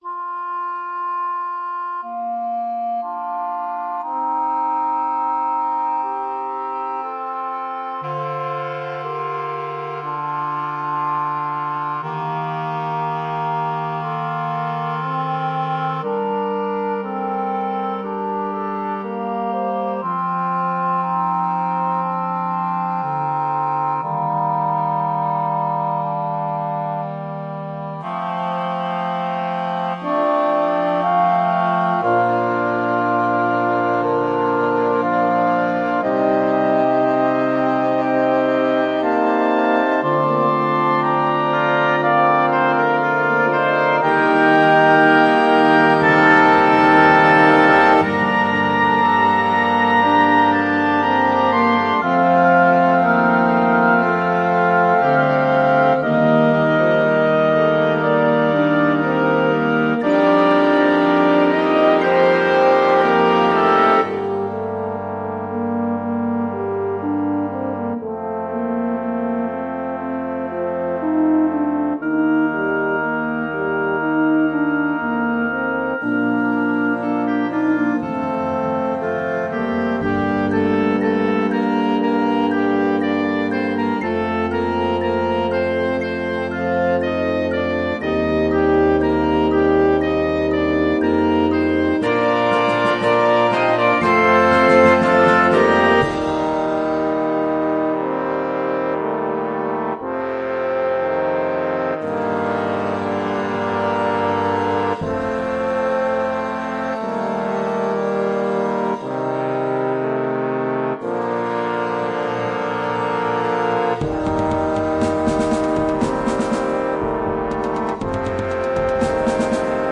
Disponible pour Brass Band, Fanfare, et Harmonie
Brass Band
Meditation / Hymne Choral